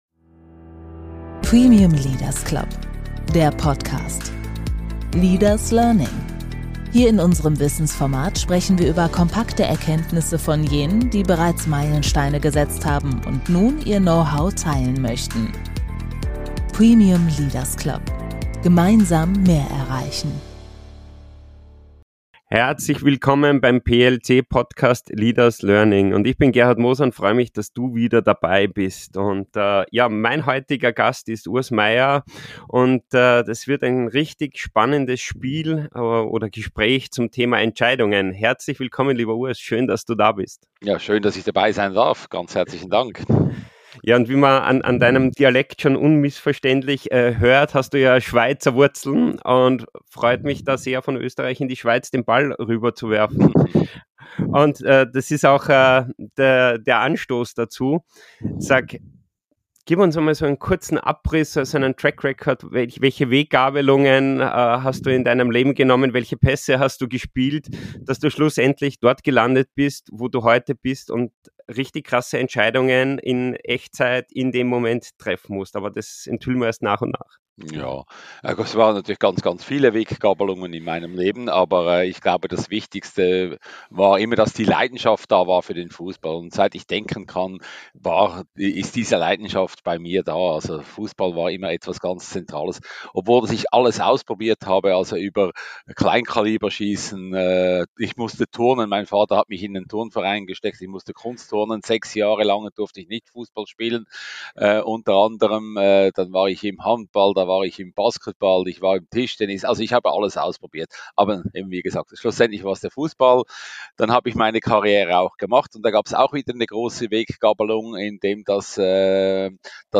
Ein Gespräch über Intuition, Verantwortung und Charakter.